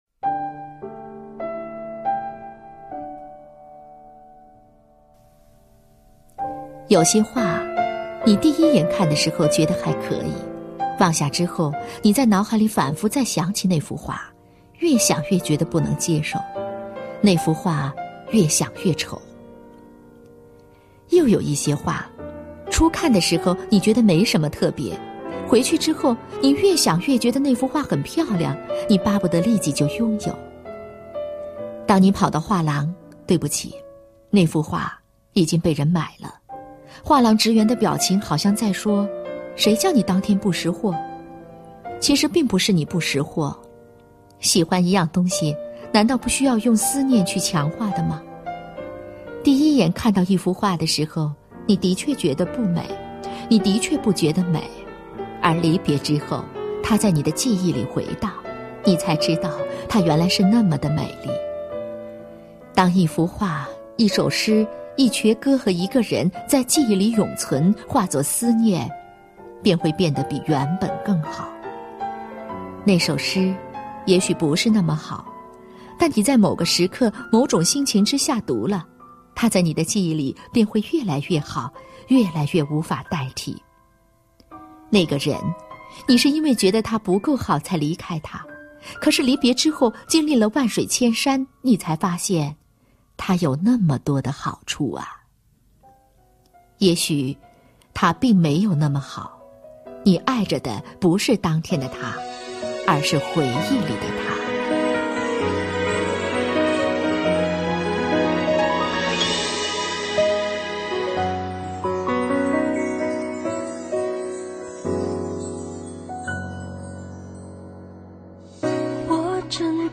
首页 视听 经典朗诵欣赏 张小娴：爱，从来就是一件千回百转的事